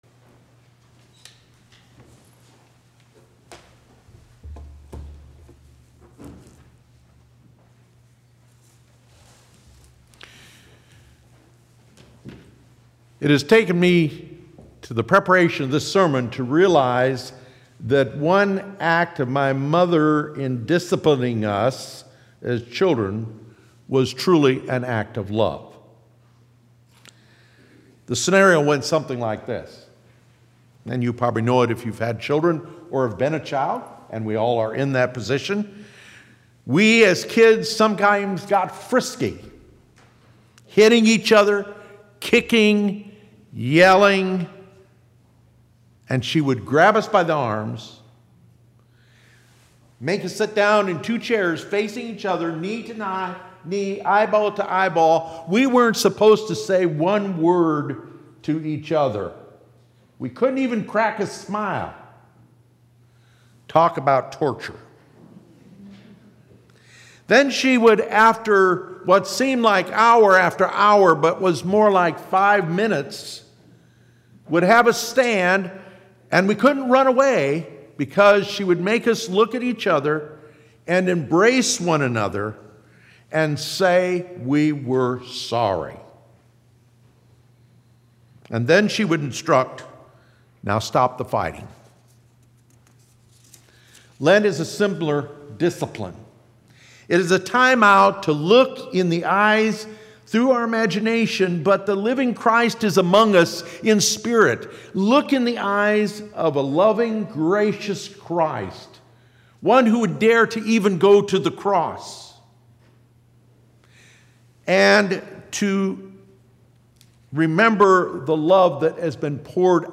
Bible Text: Romans 5:1-11 | Preacher